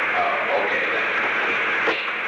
The Oval Office taping system captured this recording, which is known as Conversation 660-010 of the White House Tapes.
Location: Oval Office
The President met with an unknown person.